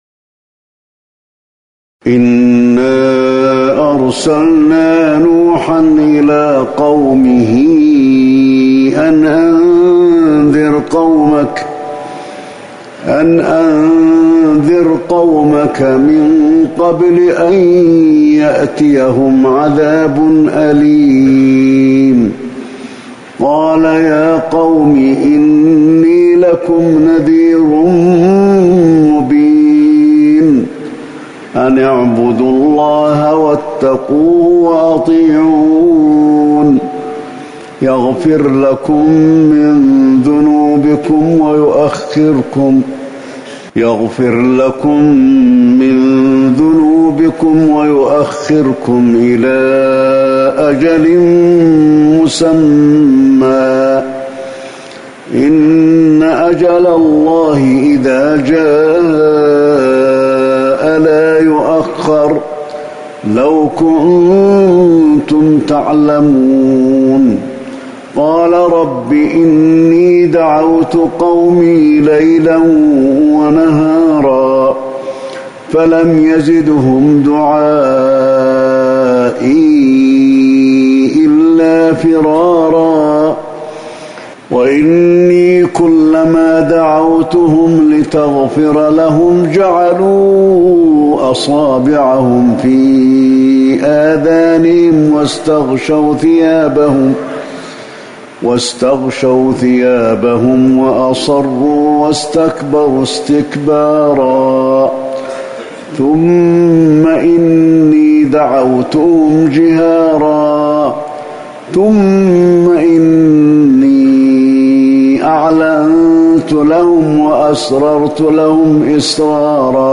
فجر 4-1-1441هـ سورتي نوح و الليل | Fajr prayer from Surat Nooh and Al-Layl > 1441 🕌 > الفروض - تلاوات الحرمين